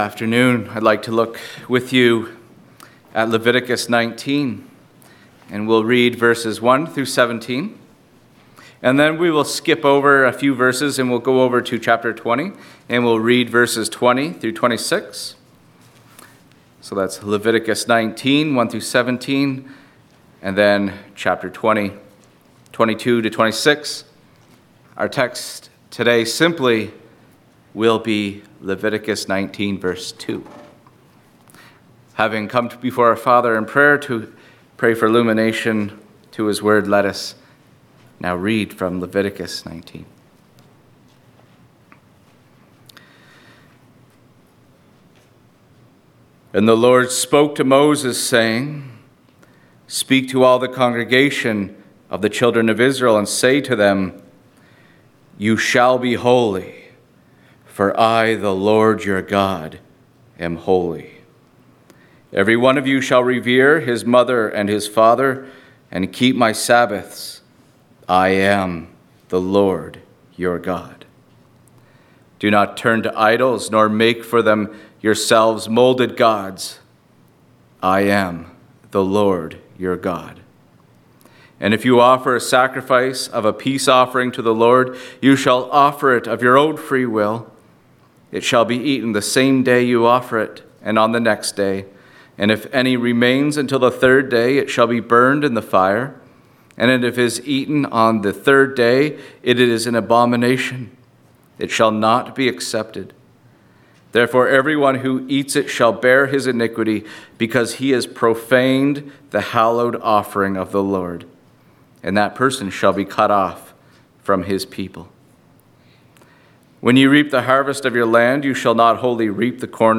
3.-sermon-Yahweh-Is-Holy.mp3